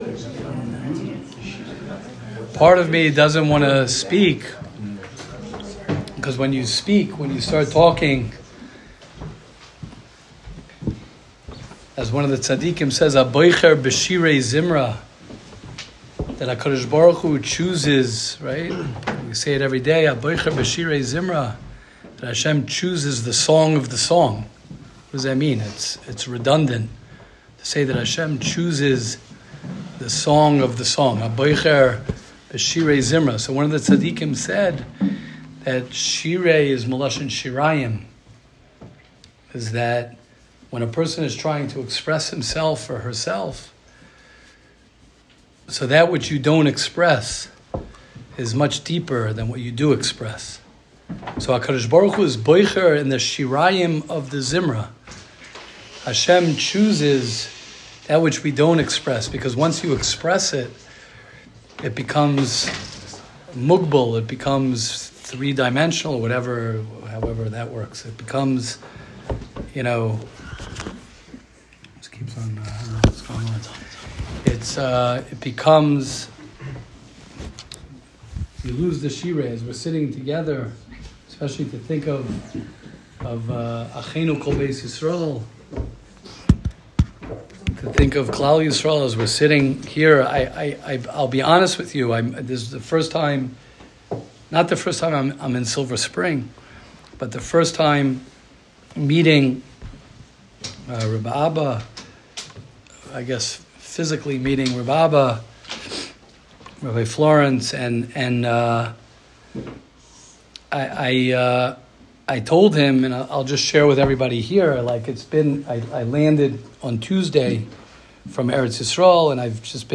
Shiur at Melava Malka in Silver Spring